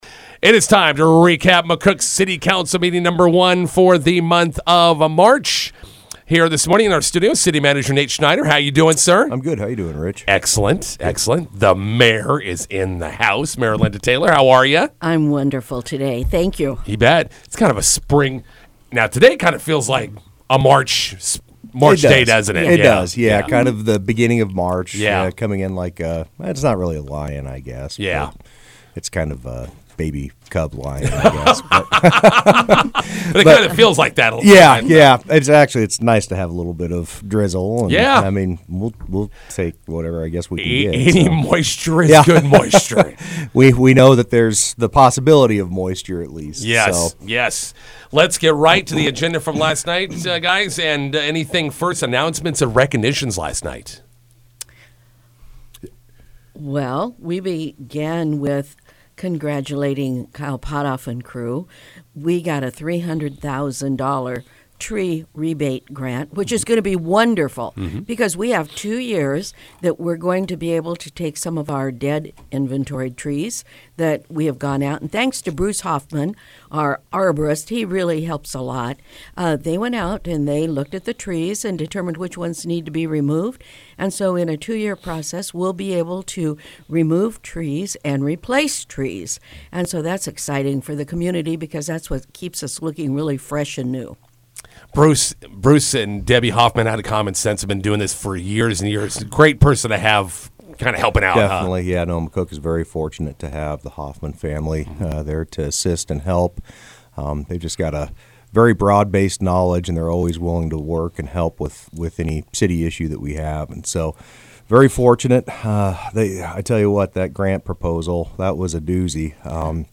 INTERVIEW: McCook City Council meeting recap with City Manager Nate Schneider and Mayor Linda Taylor.